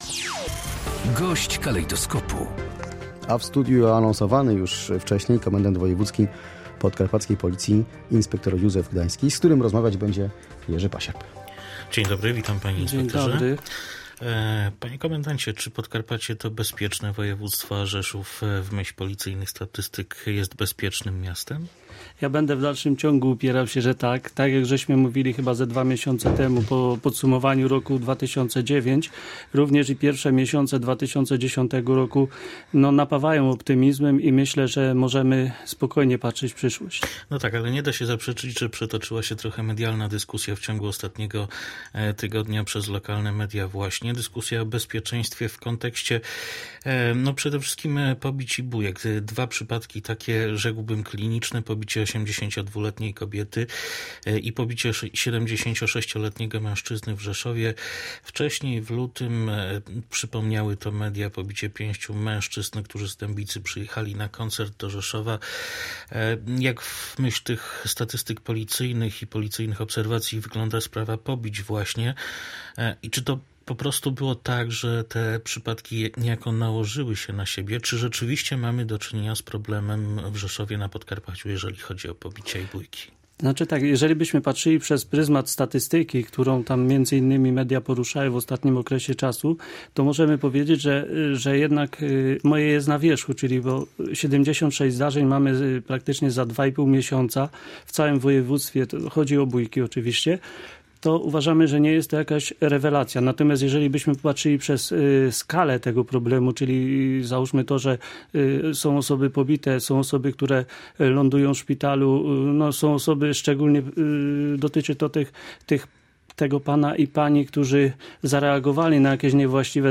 Dziś rano gościem Radia Rzeszów był inspektor Józef Gdański, Podkarpacki Komendant Wojewódzki Policji. Komendant mówił o bezpieczeństwie na Podkarpaciu. Wypowiedział się także o nowych zasadach przyznawania broni policjantom, a także o zasadach noszenia jej przez funkcjonariuszy.